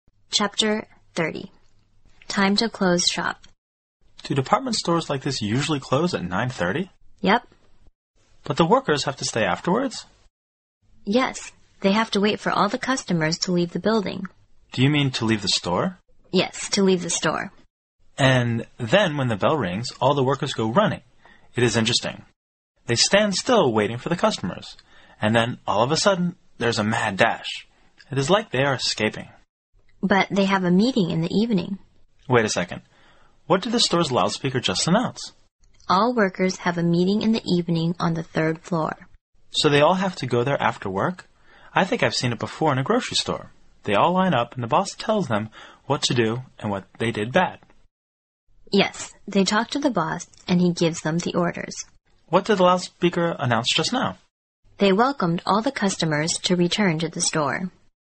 摄取生活原生态，摒弃假性交际，原汁原味的语言素材，习得口语的最佳语境。